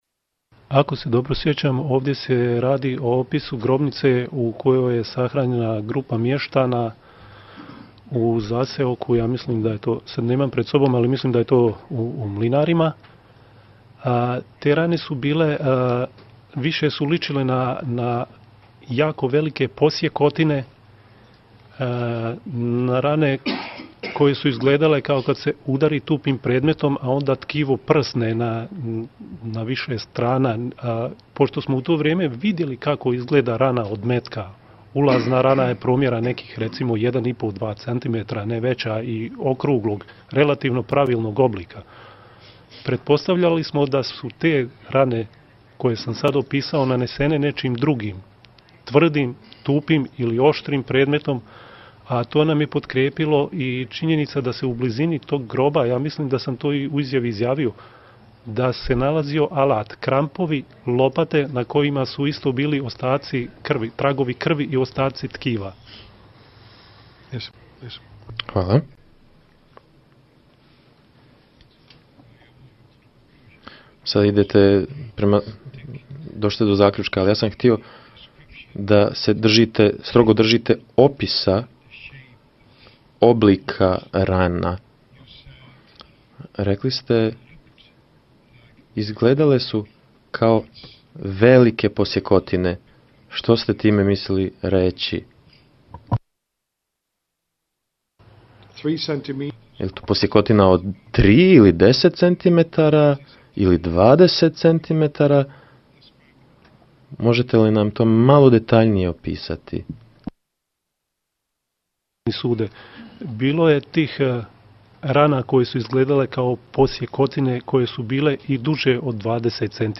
Svjedok opisuje sucima rane na civilima ubijenim tupim predmetima